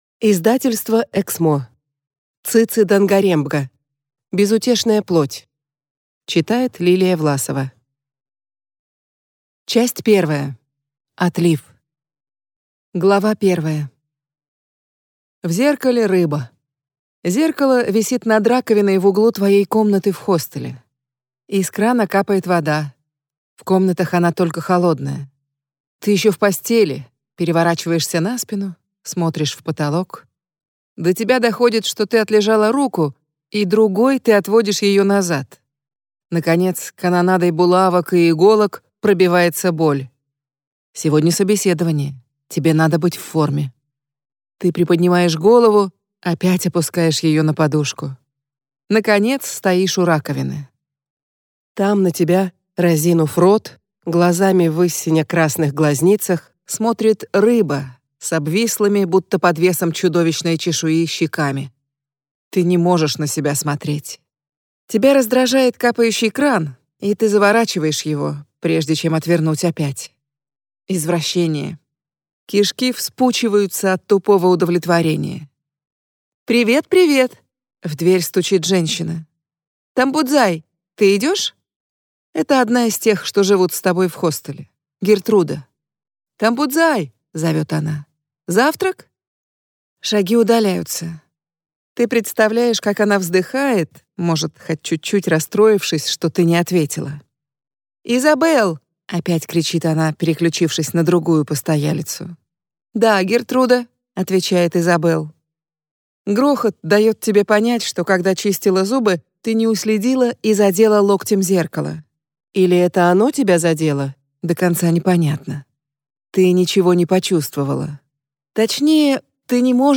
Аудиокнига Безутешная плоть | Библиотека аудиокниг